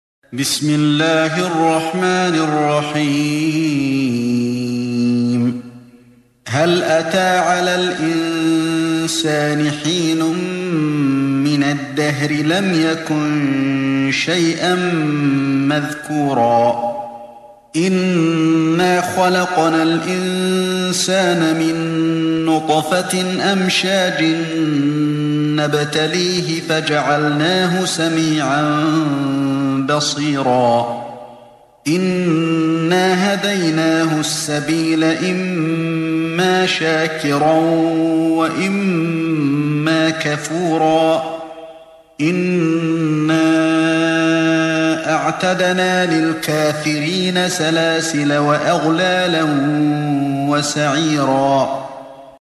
Чтение аятов 1-4 суры «аль-Инсан» шейхом ’Али бин ’Абд ар-Рахманом аль-Хузейфи, да хранит его Аллах.